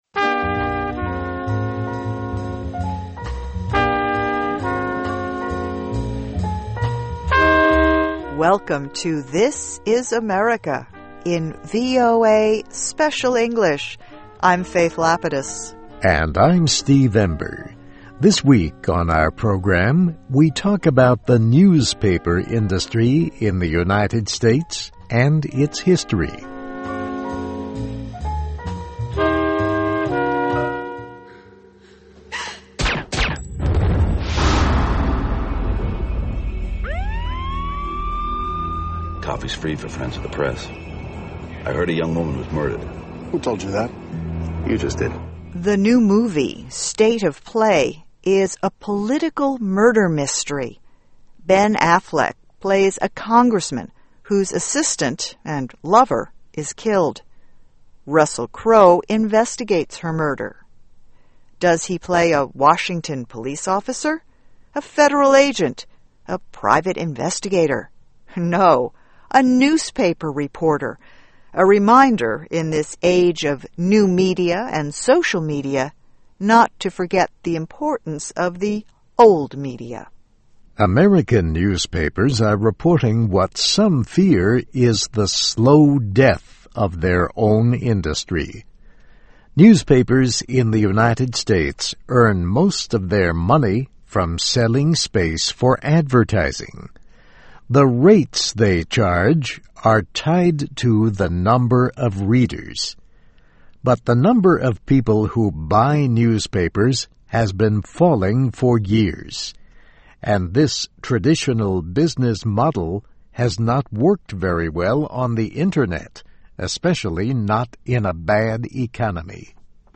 USA: How the Web Could Save Newspapers, or Kill Them (VOA Special English 2009-04-19)<meta name="description" content="Text and MP3 File.
Listen and Read Along - Text with Audio - For ESL Students - For Learning English